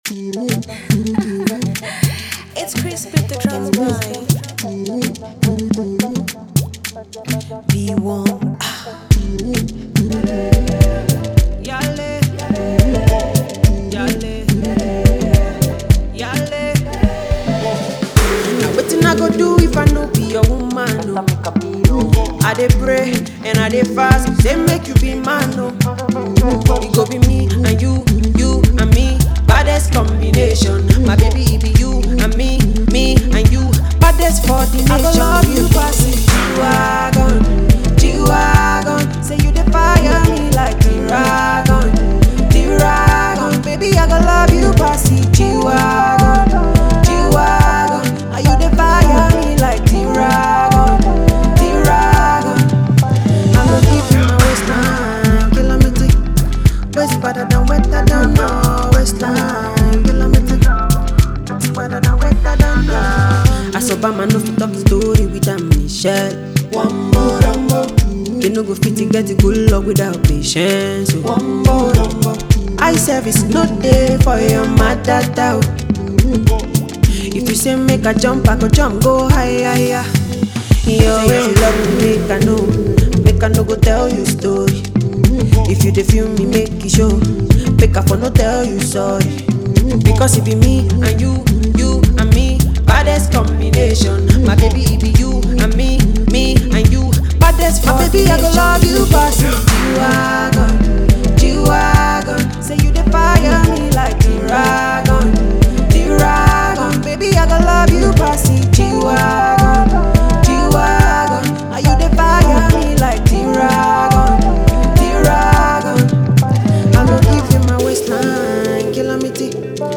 With a blend of catchy melodies and upbeat instrumentals